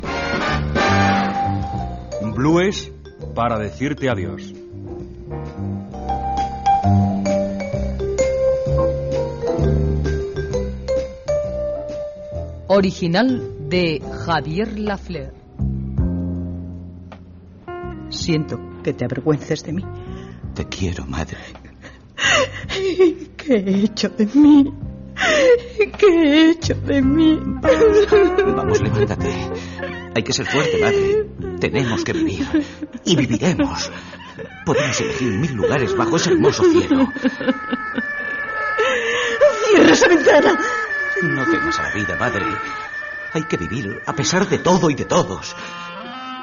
Ficció